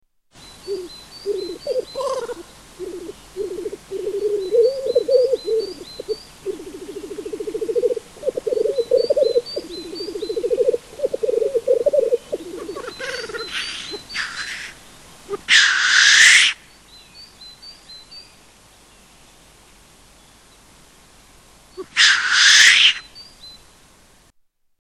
Black Grouse
Category: Animals/Nature   Right: Personal
Tags: Science and Nature Wildlife sounds Bristish Animals British Wildlife sounds United Kingdom